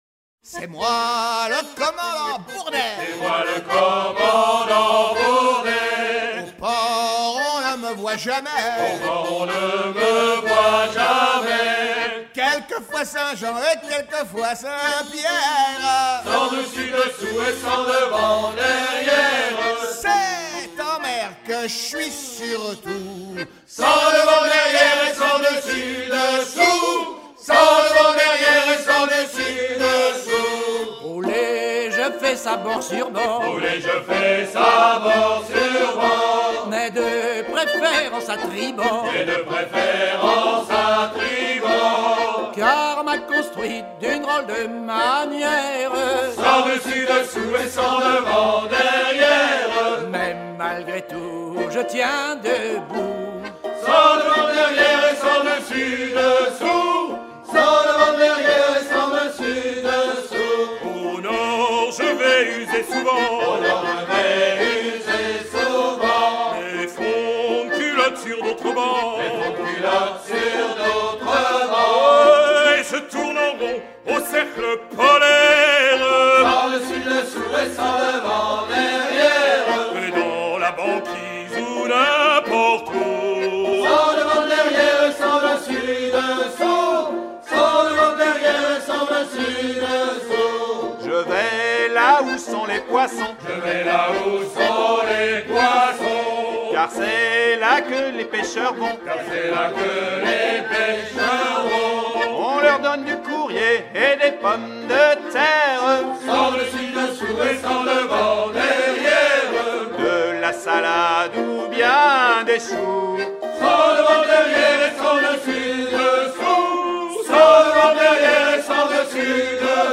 Chanson devenue l'hymne du bord
Pièce musicale éditée